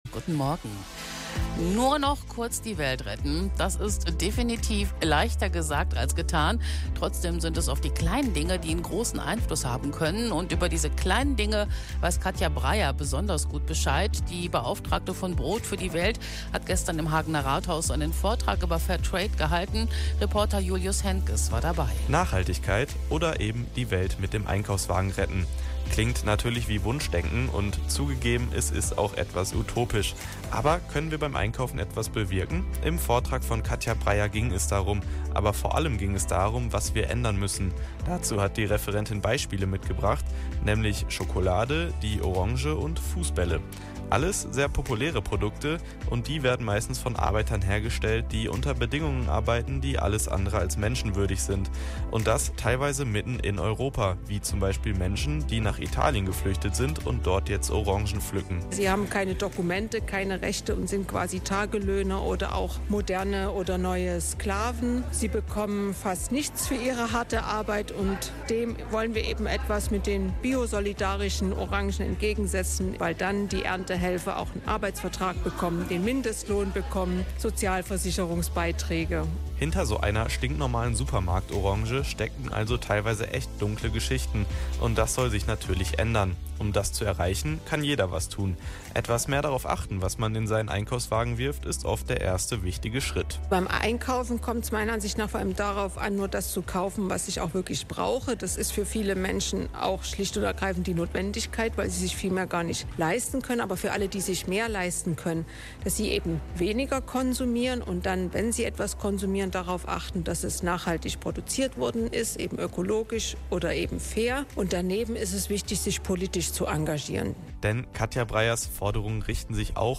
Der Radio-Beitrag zum Vortrag.